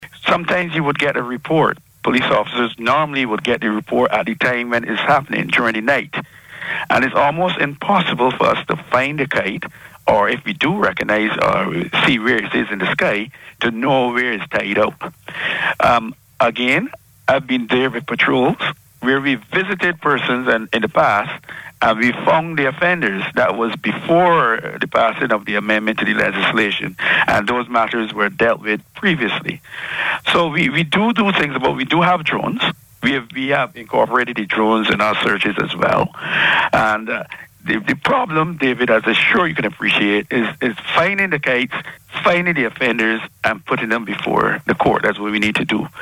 also speaking on Down to Brasstacks, responded to the concerns.